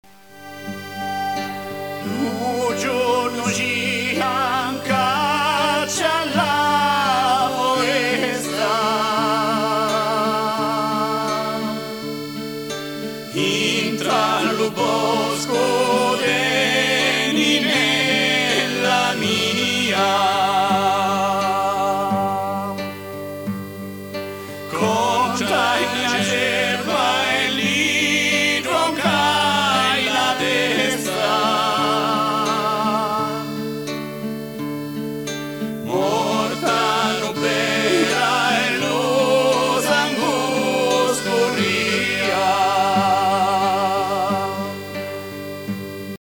Canzone tradizionale. E� una canzone allusiva, tratta il tema dell�incontro amoroso, la chiave di lettura si trova associando la foresta al corpo dell�amata.